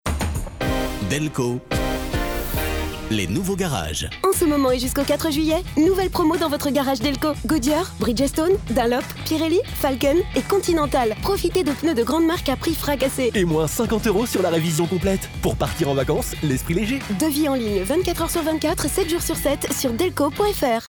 Le nouveau spot de Delko dévoile la nouvelle identité sonore du groupe, développée pour marquer les esprits et favoriser la mémorisation de ses annonces.
SPOT-RADIO-DELKO_mai-2021.mp3